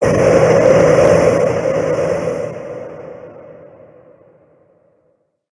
Cri de Mackogneur Gigamax dans Pokémon HOME.
Cri_0068_Gigamax_HOME.ogg